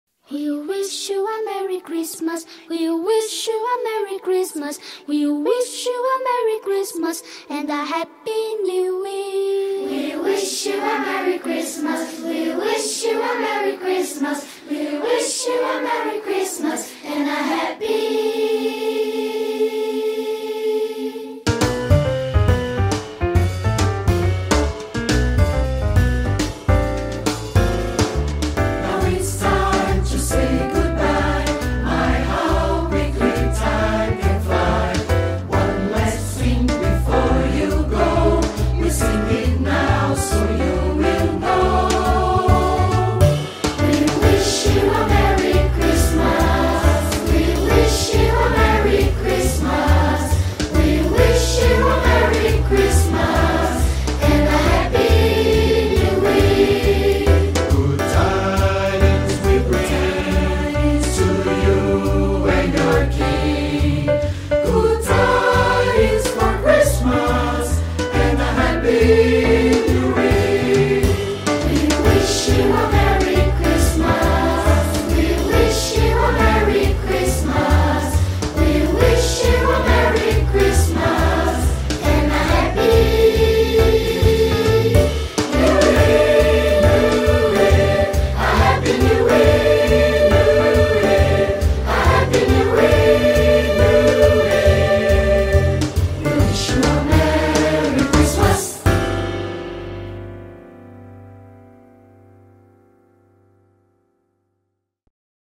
Cantada pelo Coral CAA OAB – Maringa